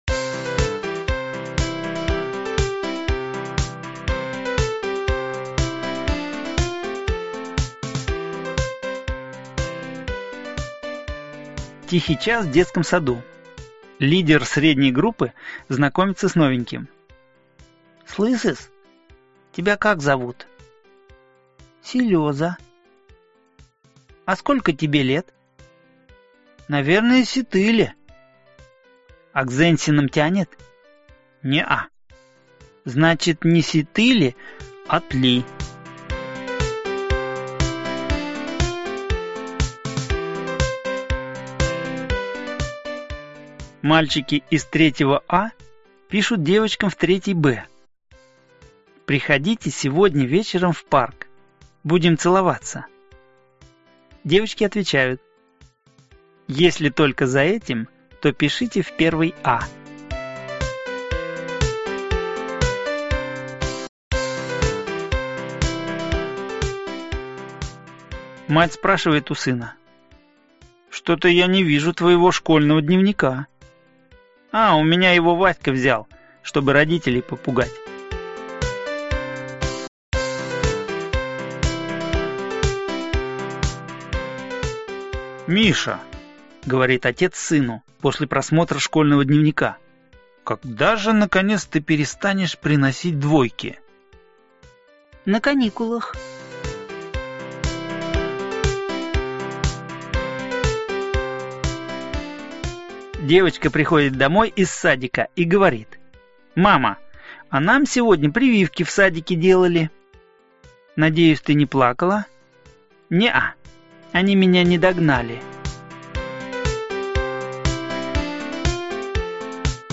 Аудиокнига Анекдоты 2 | Библиотека аудиокниг